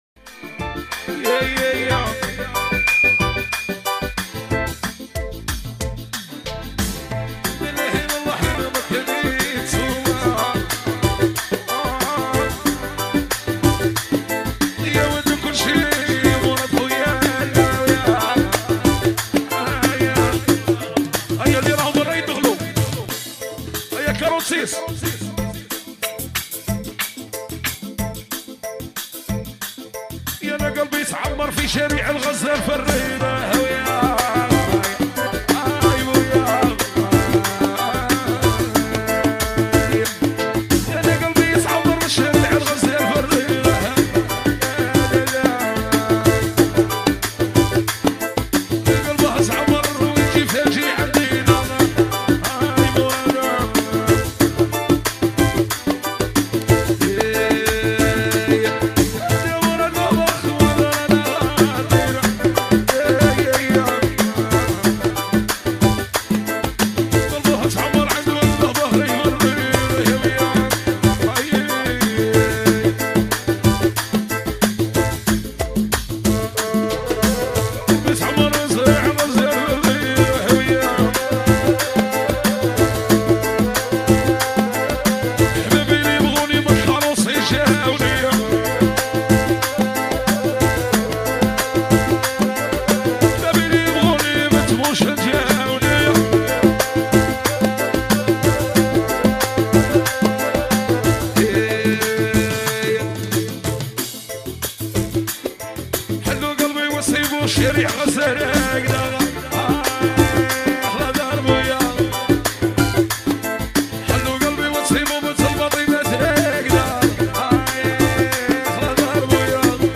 " اغاني راي جزائري "